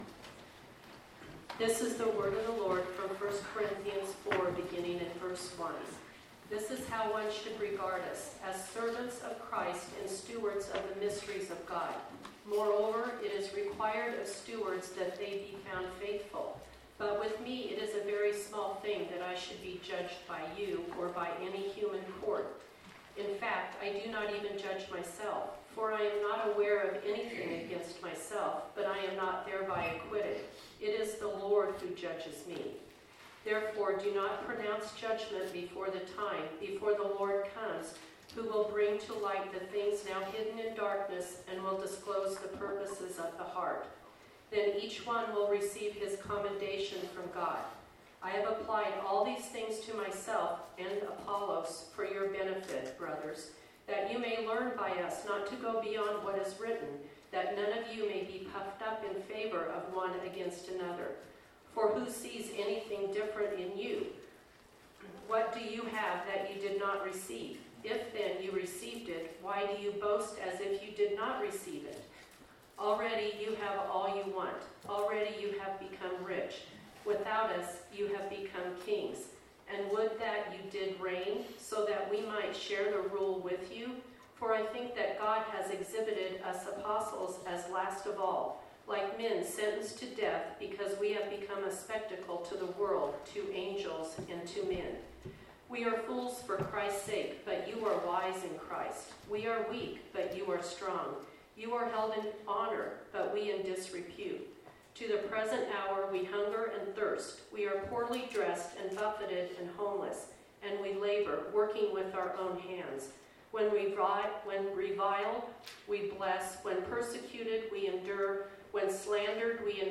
Service Type: Sunday Morning Topics: be faithful to God , follow the Master , Judgment , prayful not prideful , Who are believers?